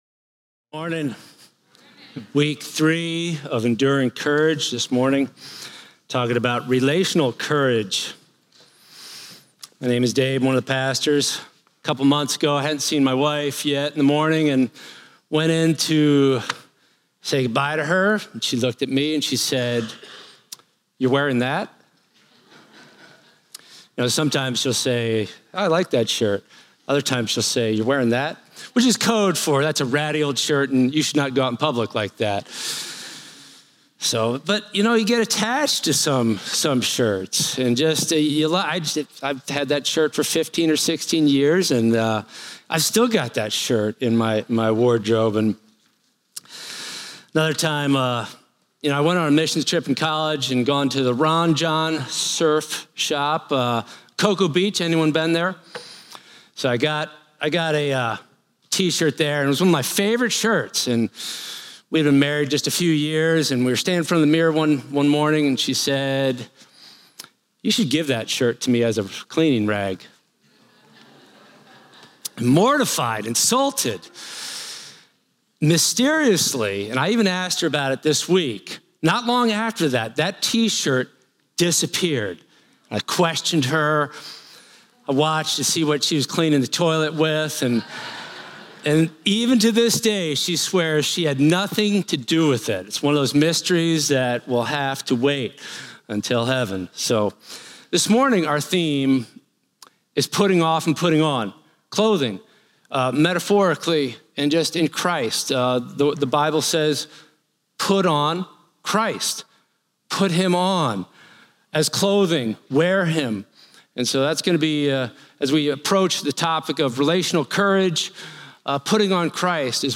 preaches from Colossians 3:5-17.